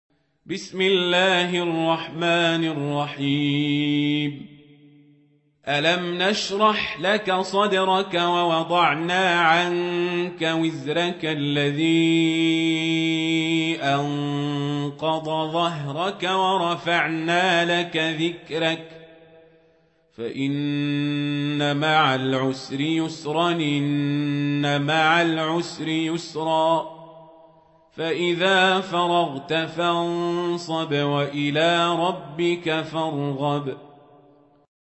سورة الشرح | القارئ عمر القزابري